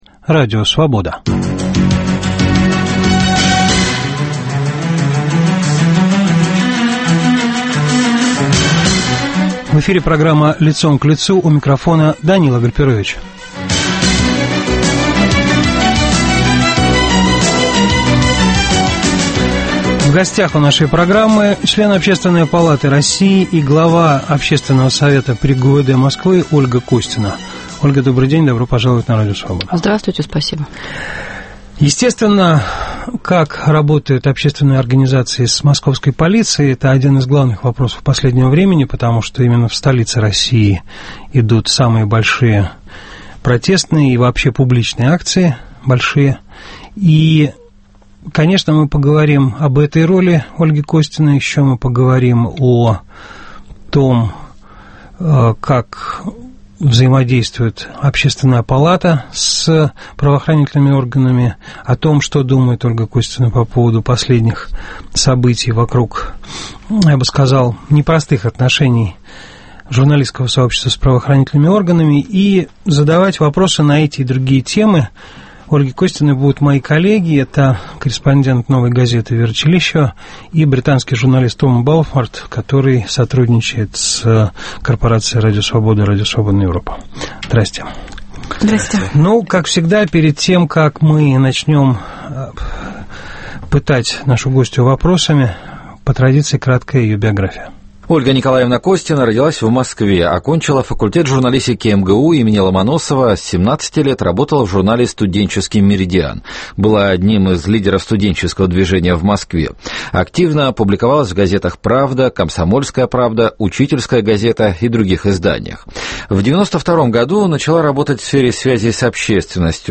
В программе - глава Общественного совета при ГУВД Москвы, член Общественной палаты России Ольга Костина.